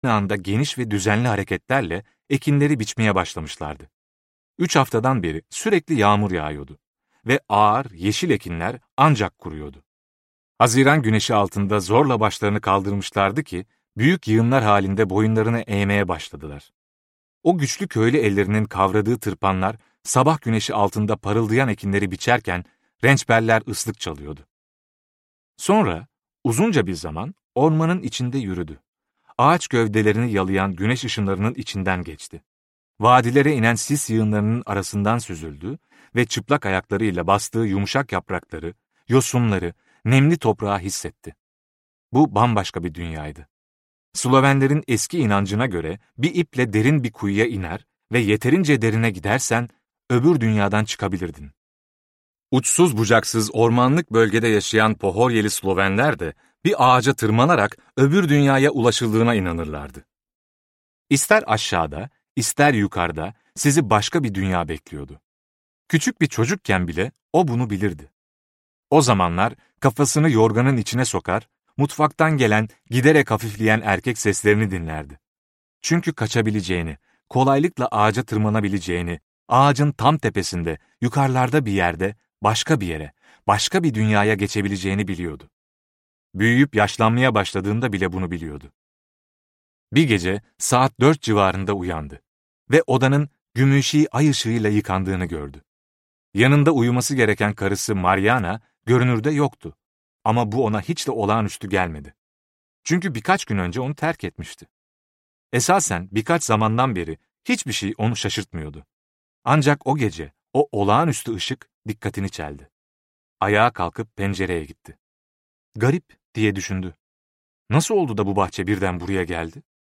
Adsız Ağaç - Seslenen Kitap